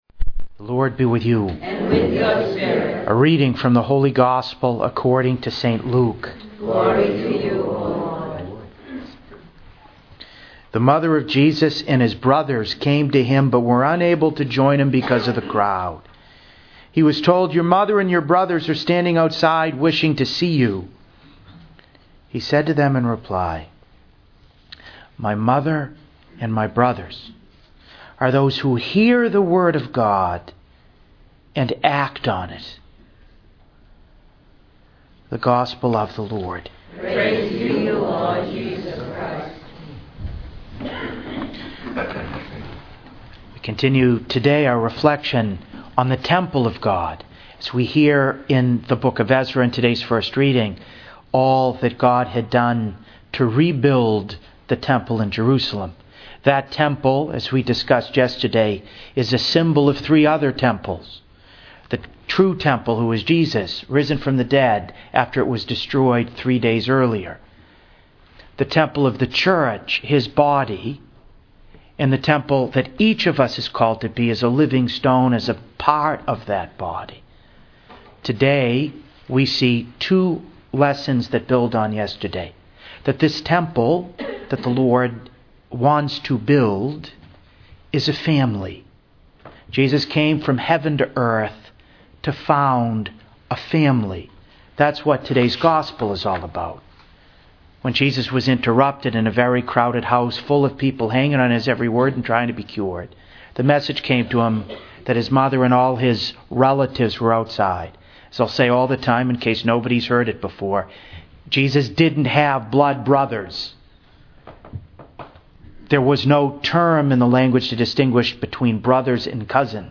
St. Bernadette Parish, Fall River, MA